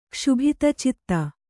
♪ kṣubhita citta